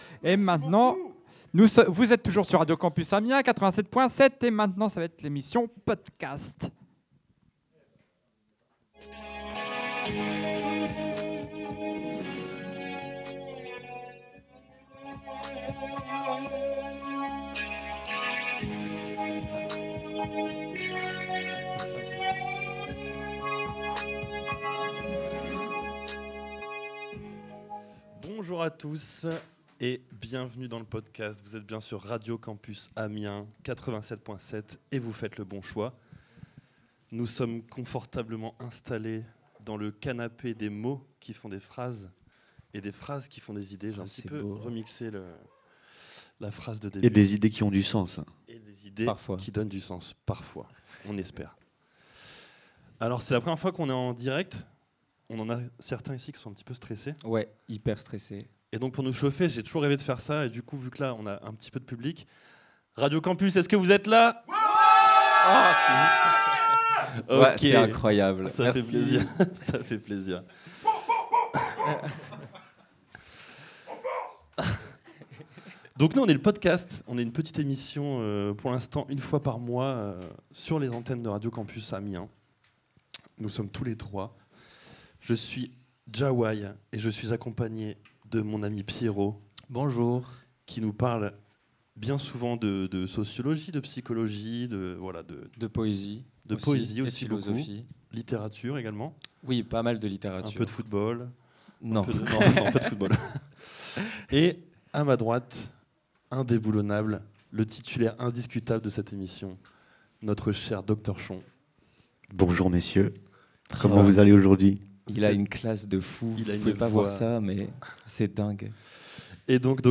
Samedi 22 mars, les animateurs et animatrices Radio Campus sur scène au Chaudron - Scène étudiante du Crous et en direct !
L’équipe de Potekast est en direct, sur scène… et va même chercher le public pour intervenir !